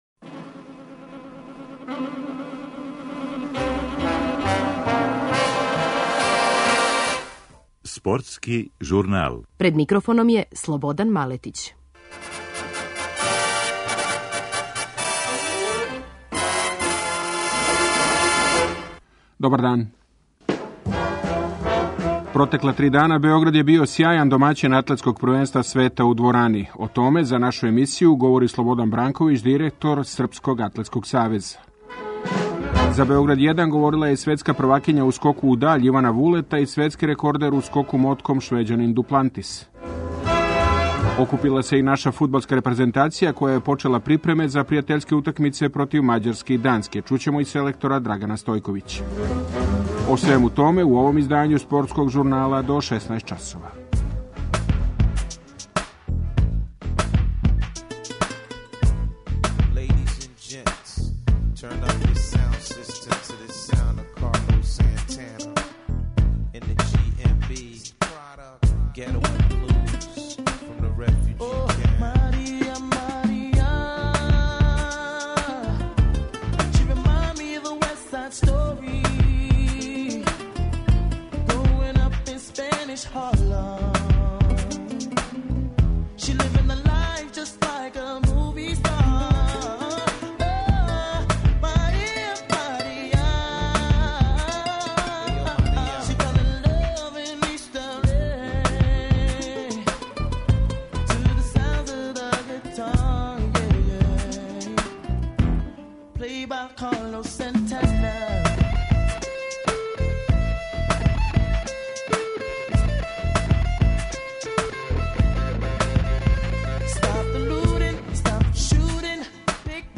За Београд 1 говорила је и светска првакиња у скоку у даљ Ивана Вулета и светски рекордер у скоку мотком Швеђанин Дуплантис.
Окупила се и наша фудбалска репрезентација, која је почела припреме за пријатељске утакмице против Мађарске и Данске. Чућемо и селектора Драгана Стојковића.